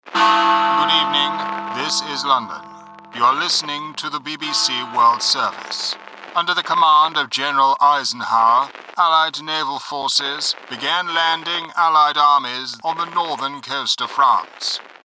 Naturally deep, warm and friendly, distinguished, charismatic, versatile
RP